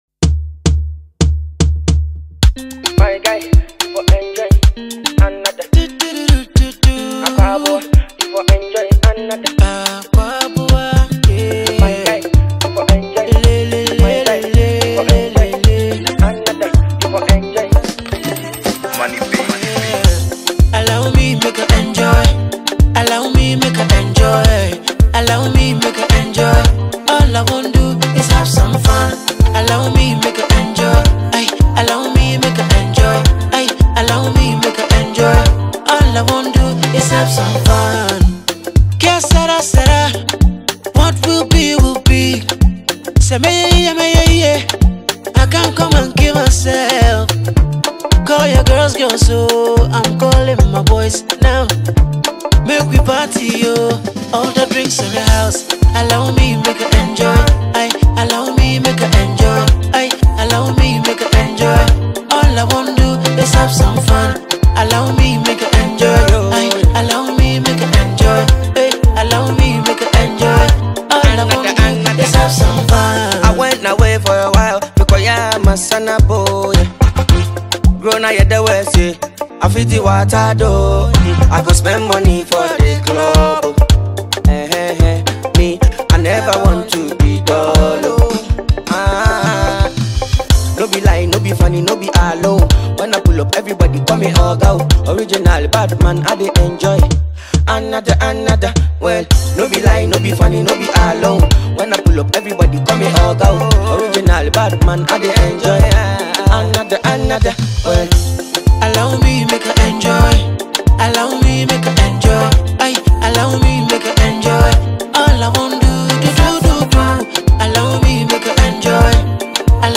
Ghana Music
Ghanaian highlife award winning singer